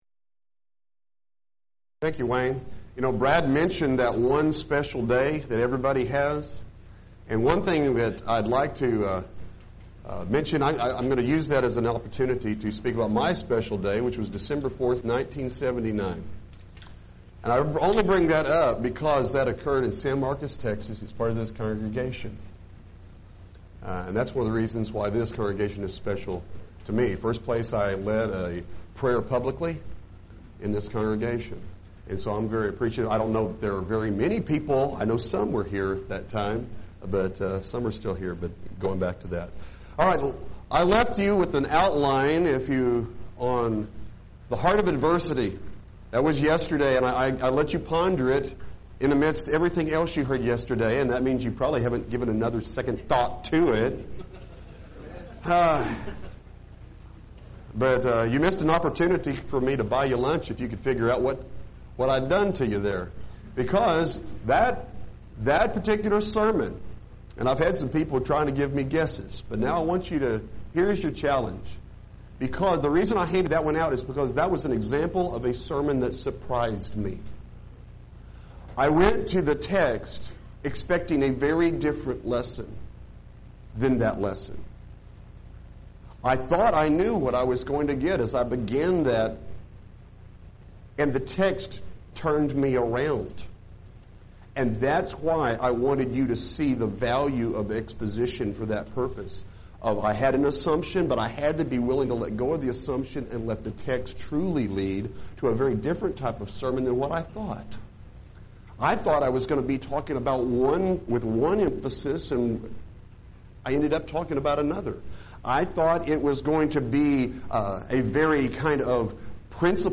Event: 2018 Focal Point Theme/Title: Preacher's Workshop
lecture